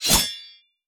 slash-sword.wav